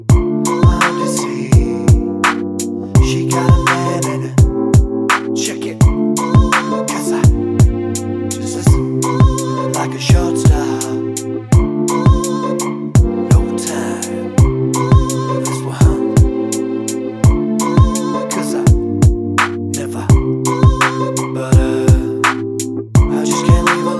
No Backing Vocals R'n'B / Hip Hop 4:03 Buy £1.50